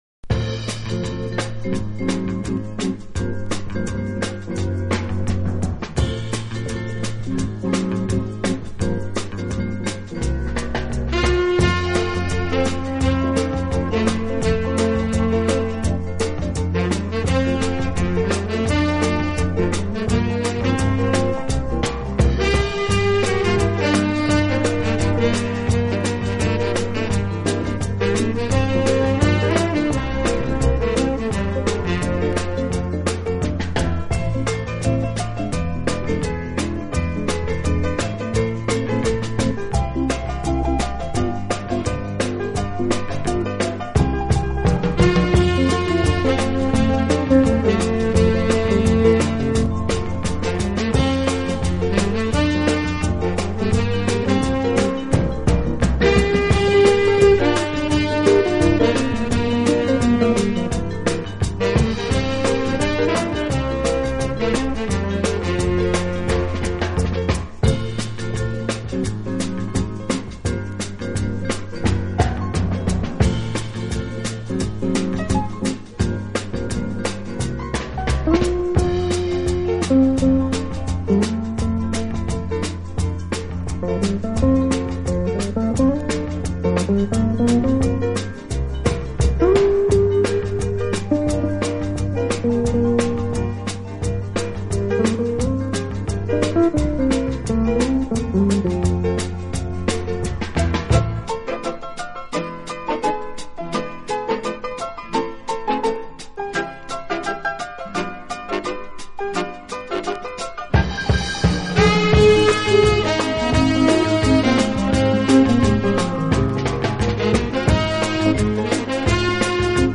音乐类型：Easy Listening/Big Band, Easy Pop, Orchestral